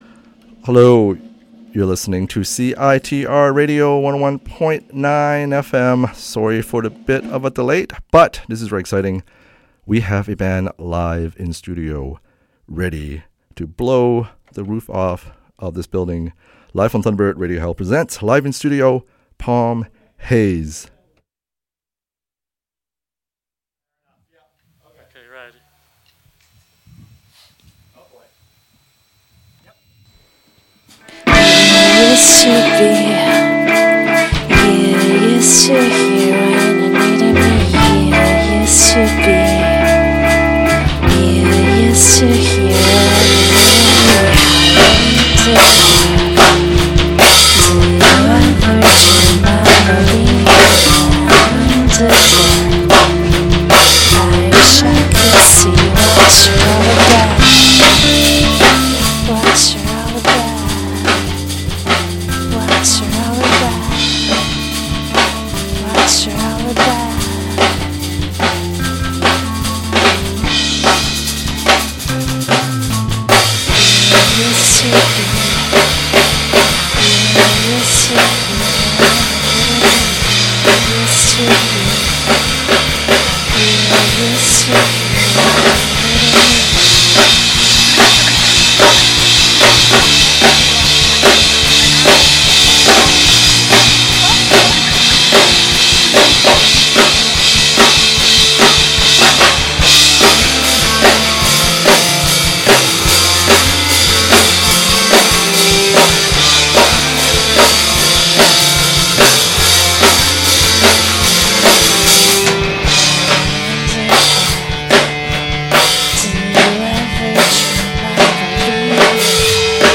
Live in studio performance